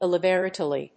音節il・lib・er・al・i・ty 発音記号・読み方
/ɪ(l)lìbərˈæləṭi(米国英語)/